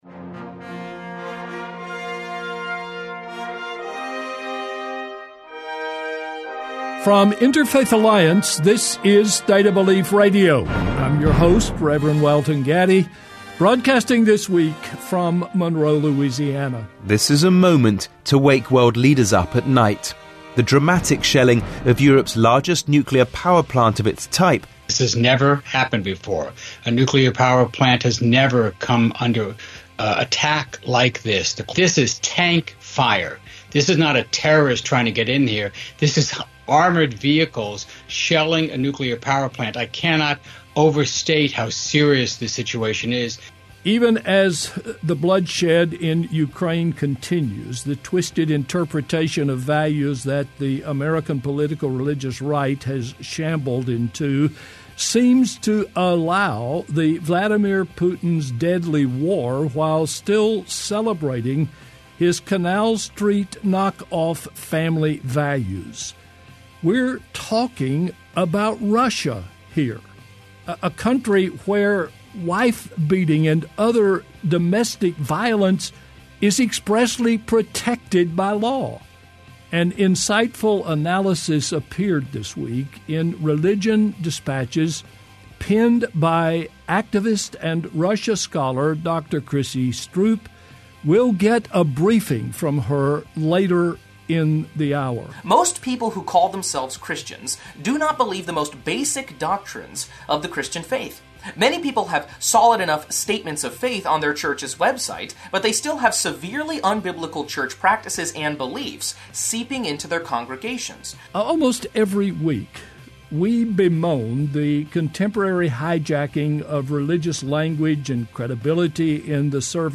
This week on State of Belief, Interfaith Alliance’s weekly radio show and podcast, we will examine these narratives that favor the beliefs of some over the rights of all, and discuss how we can challenge these dangerous ideas.